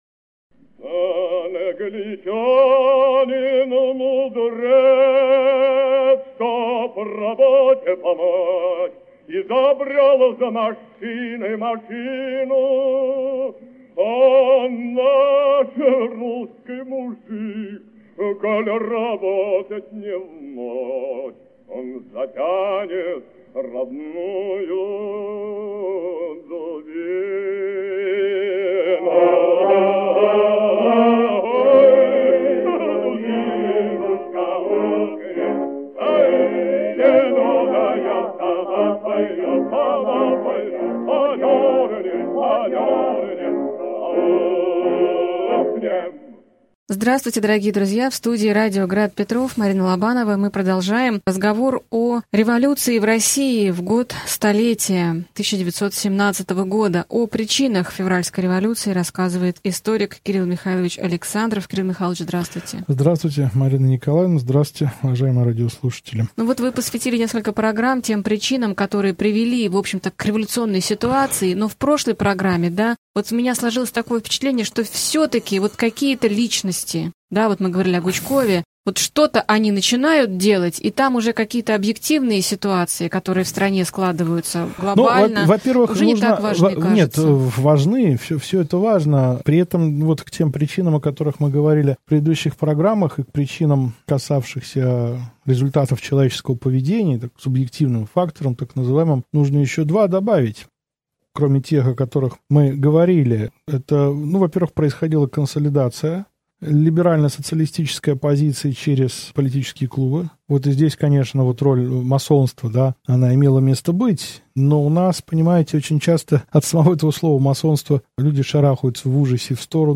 Аудиокнига Февральская революция и отречение Николая II. Лекция 9 | Библиотека аудиокниг